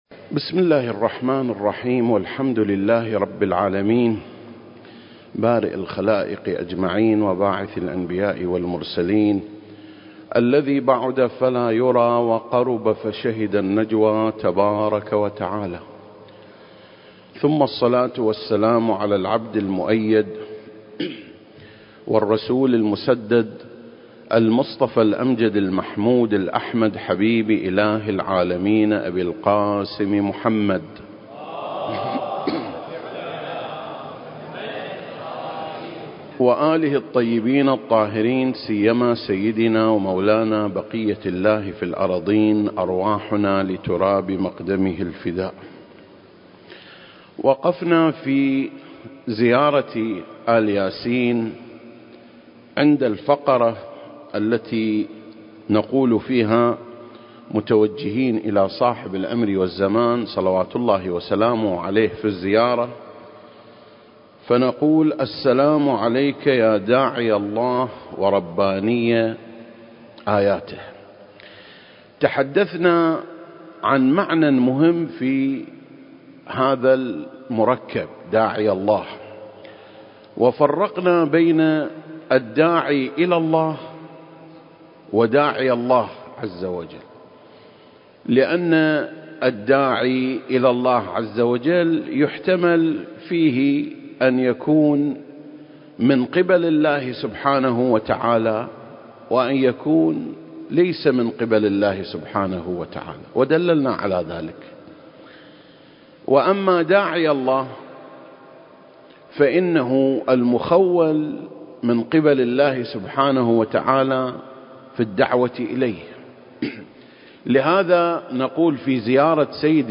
سلسلة: شرح زيارة آل ياسين (24) - يا داعي الله (2) المكان: مسجد مقامس - الكويت التاريخ: 2021